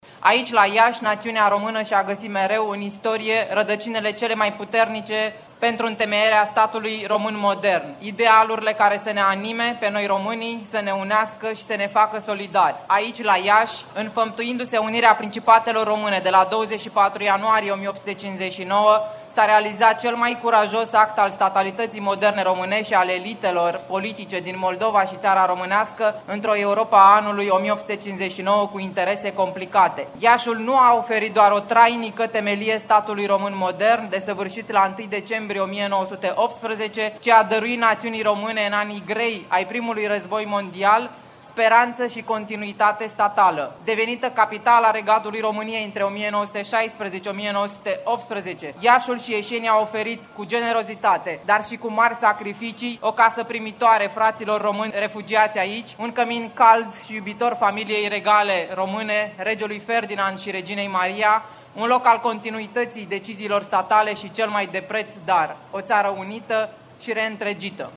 Aproximativ 5.000 de persoane au participat, astăzi, în Piaţa Unirii din Iaşi la manifestările organizate pentru a marca importanţa Unirii de la 1859.
Mesajul Casei Regale a fost prezentat de senatorul liberal de Iaşi, Iulia Scântei în care s-a arătat vocaţia Iaşului drept „Oraş al Unirii”: